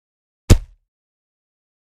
Звук бега в бронежилете для монтажа